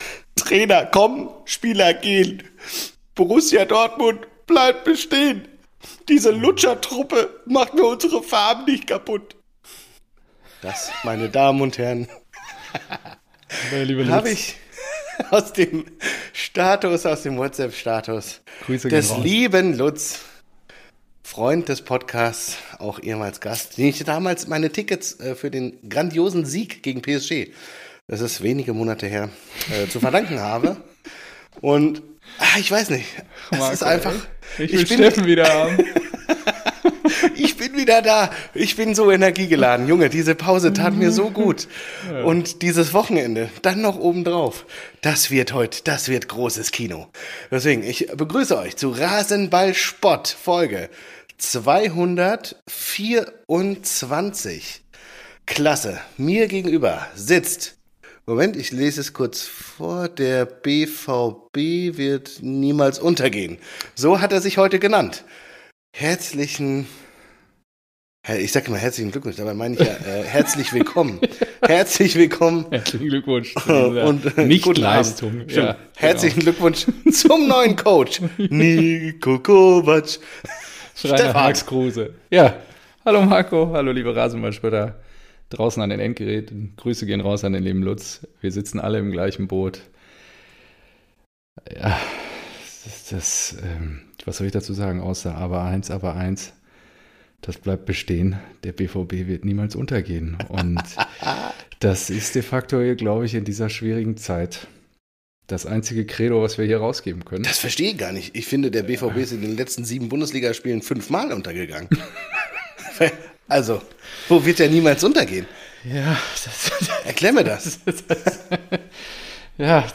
Ein BVB-Fan quatscht mit seinem SGE-Kumpel. Der Podcast für alle Vereine, die vor 2009 gegründet wurden.